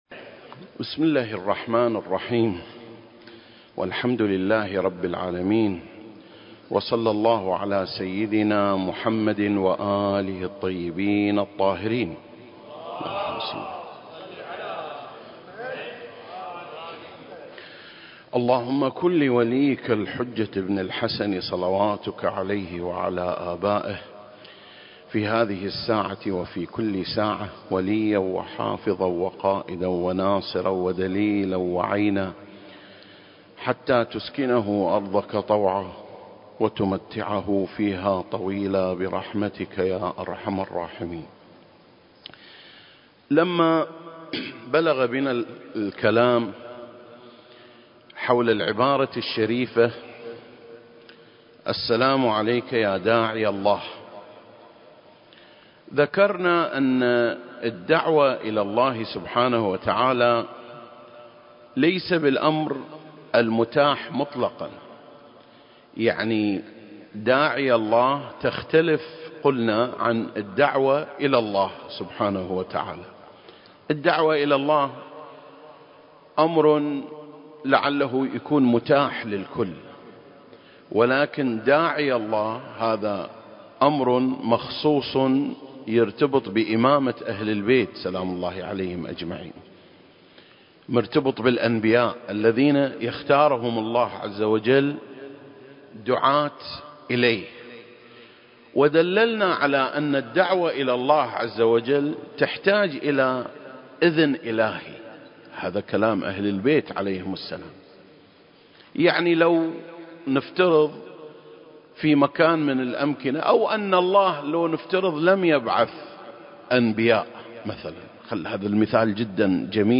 سلسلة: شرح زيارة آل ياسين (27) - سعة الدعوة المهدوية (1) المكان: مسجد مقامس - الكويت التاريخ: 2021